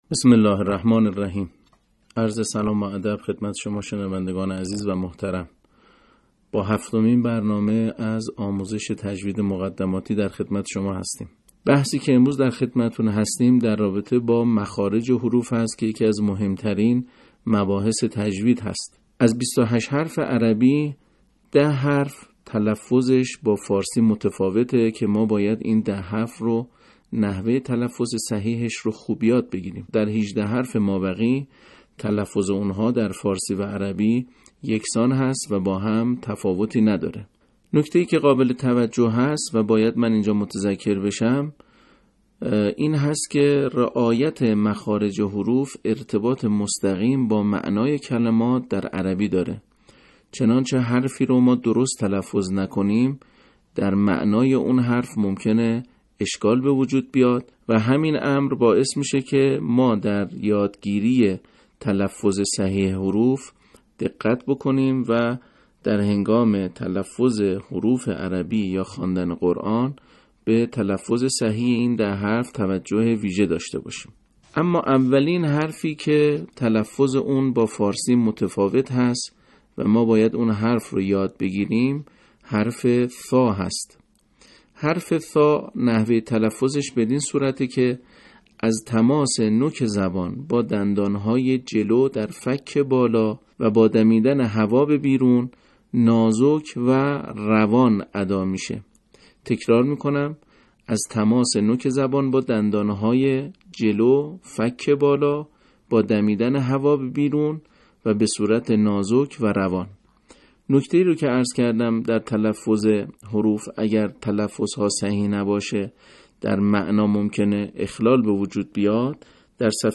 آموزش تجوید